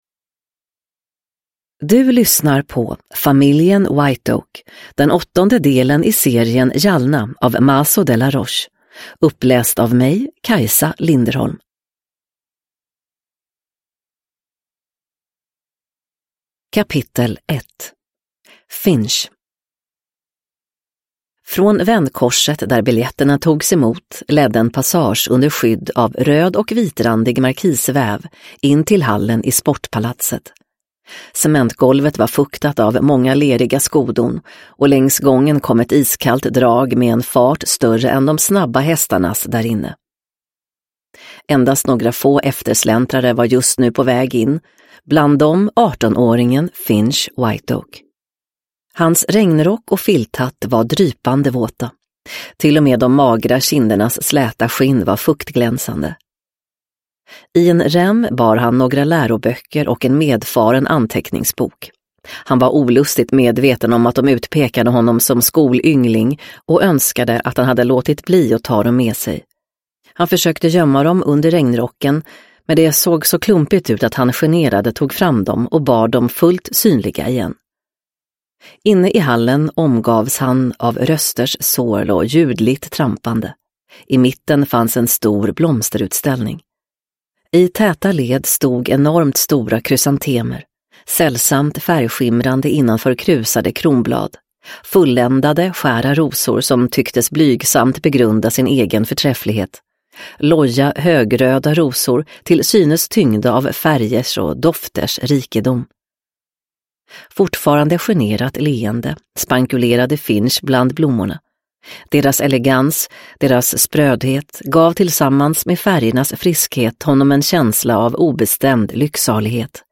Familjen Whiteoak – Jalna 8 – Ljudbok – Laddas ner